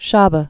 (shäbə)